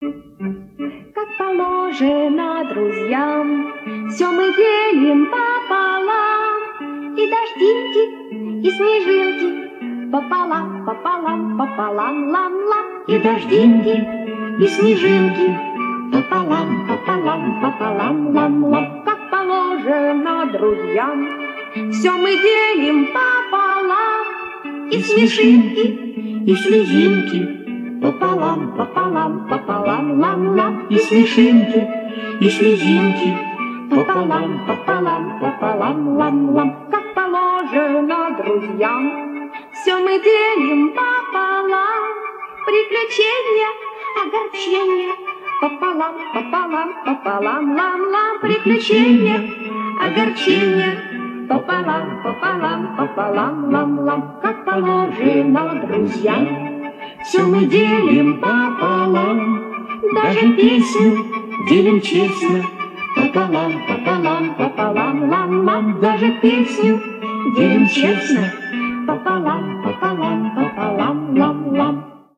веселой детской песенки